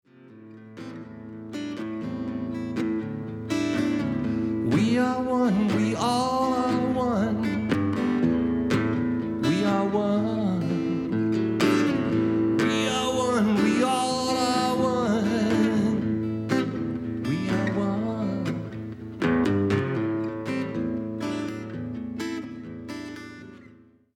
Original Song from fundraiser concert 2016